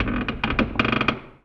metal_low_creak_squeak_03.wav